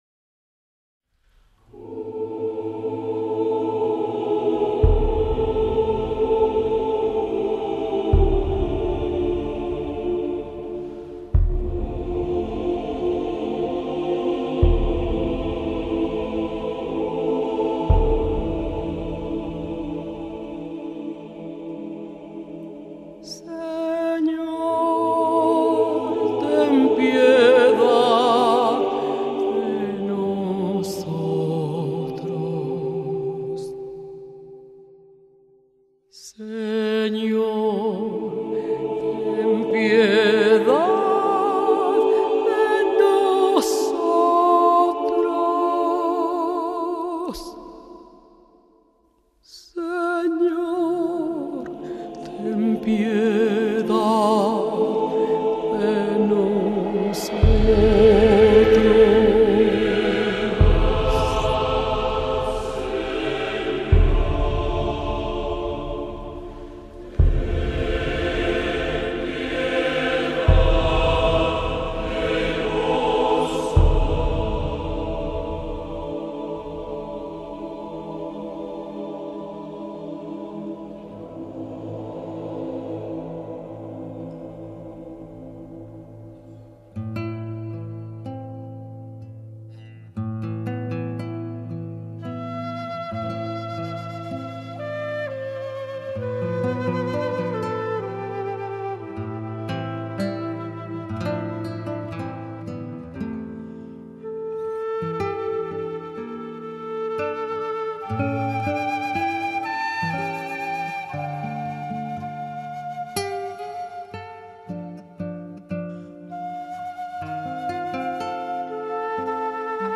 她的声音非常独特：戏剧性的、完美的、感情丰满。